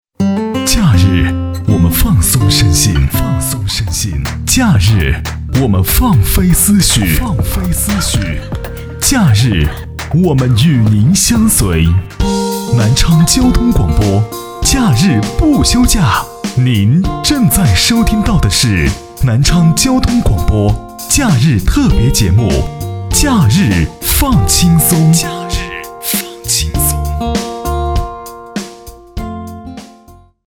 • 男S317 国语 男声 广告-电台节目广告-轻松舒适 沉稳|积极向上|素人